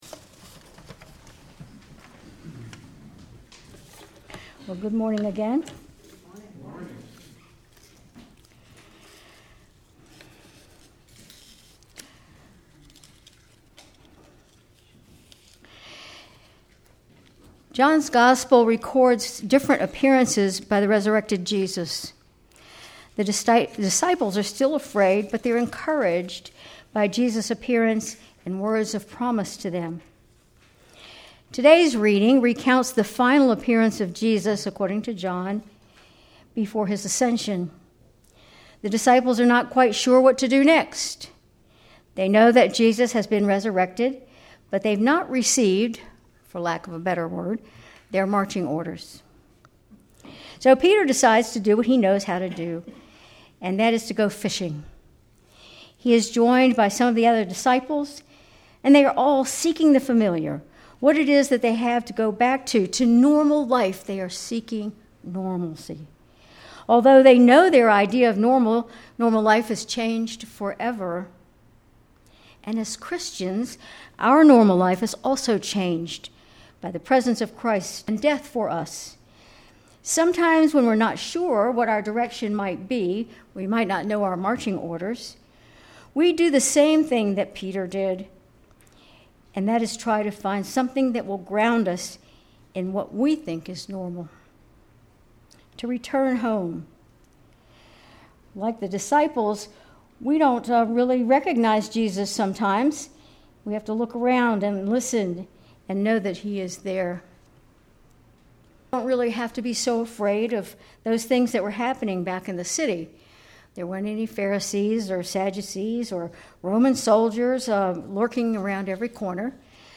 Sermon May 4, 2025
Sermon_May_4_2025.mp3